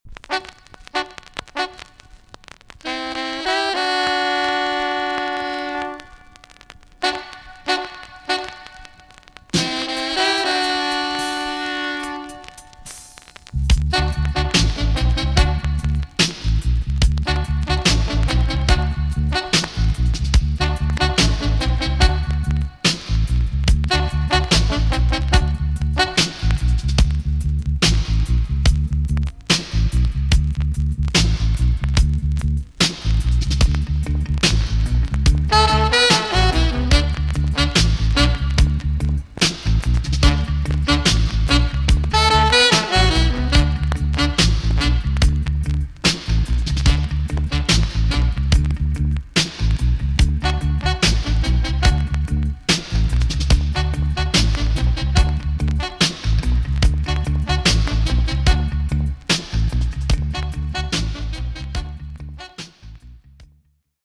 B.SIDE Version
VG+ 軽いチリノイズが入ります。